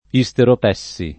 vai all'elenco alfabetico delle voci ingrandisci il carattere 100% rimpicciolisci il carattere stampa invia tramite posta elettronica codividi su Facebook isteropessi [ i S terop $SS i ] o isteropessia [ i S terope SS& a ] s. f. (med.)